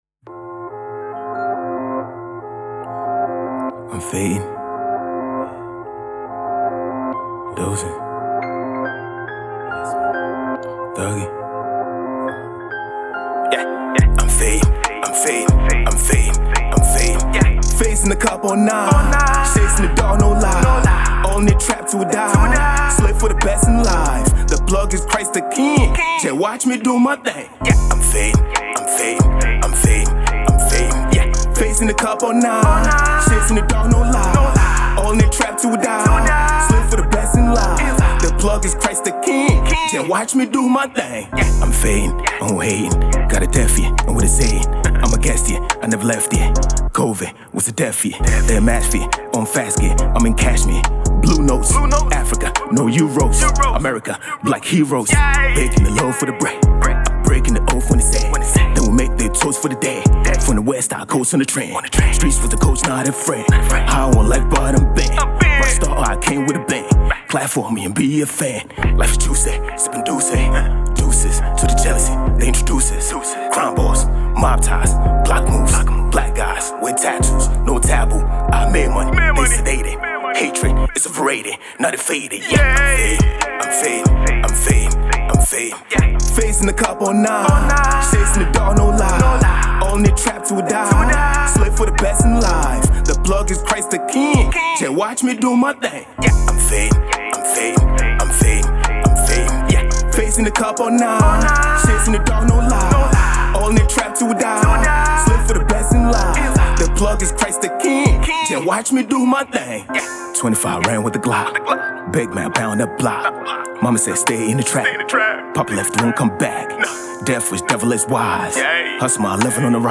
hip-hop Rap and Afrobeat